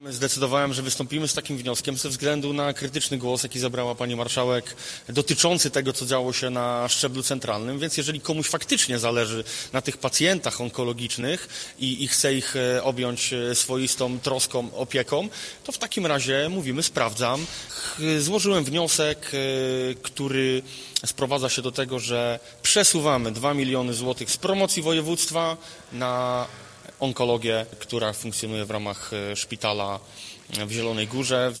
Czy rządząca województwem koalicja PO-PSL-SLD zrezygnuje z części wydatków na promocję regionu i przeznaczy je na walkę z rakiem? Taki postulat pojawił się podczas poniedziałkowej sesji lubuskiego sejmiku. Radny Łukasz Mejza zaapelował by np. zamiast na bilbordy z wizerunkiem marszałek Elżbiety Anny Polak, pieniądze przeznaczyć na oddział onkologiczny Szpitala Uniwersyteckiego w Zielonej Górze